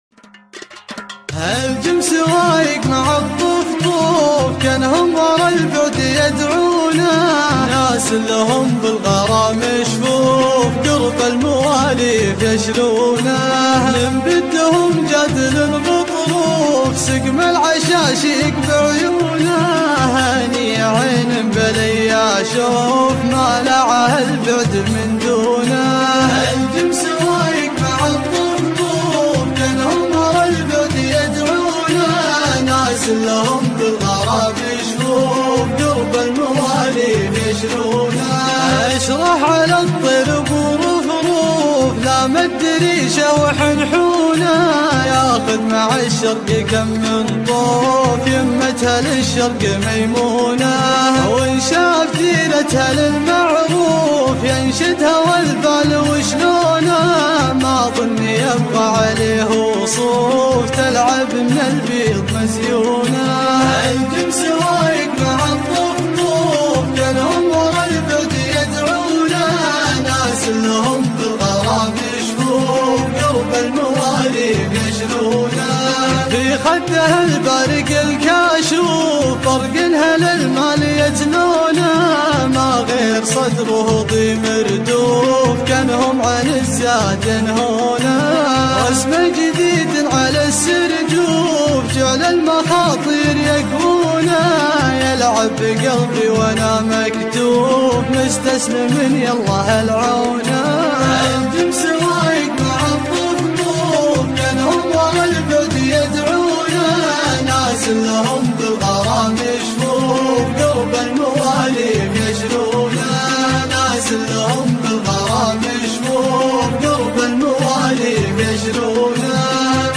شيلة &quot